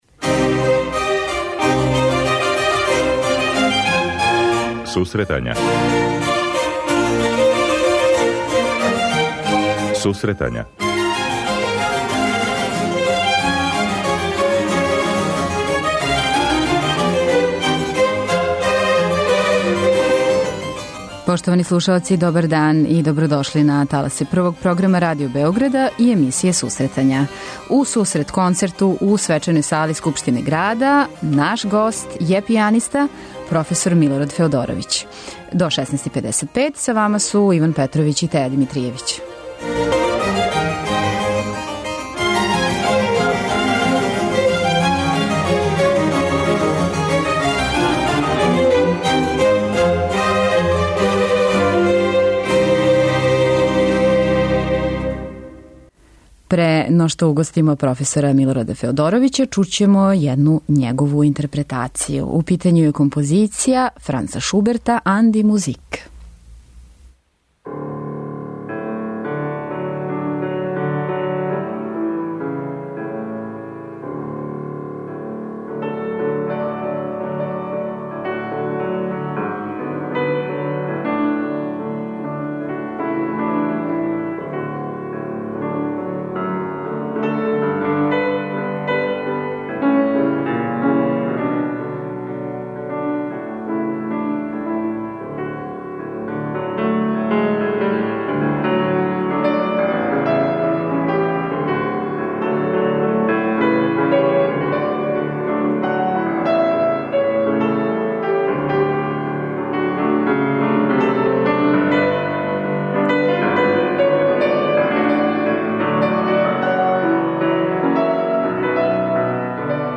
гост емисије биће пијаниста